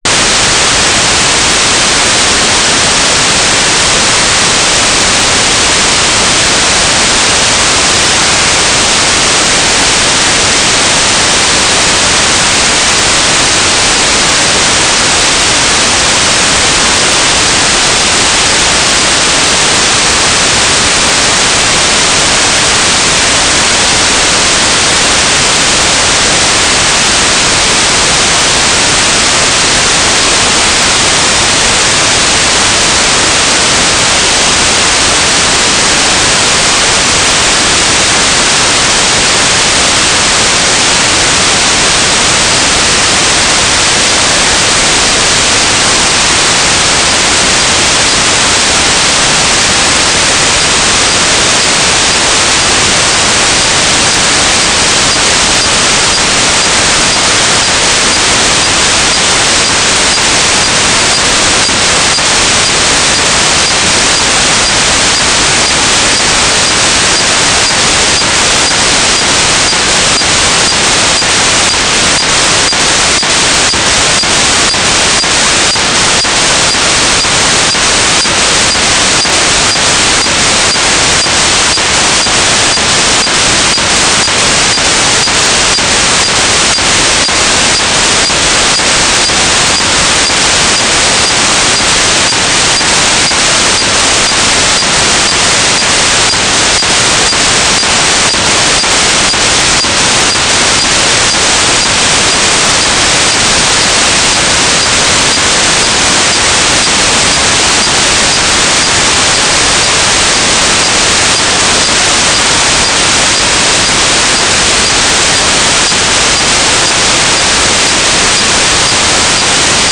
"transmitter_description": "Mode U - GMSK9k6 - TLM",
"transmitter_mode": "MSK AX.100 Mode 5",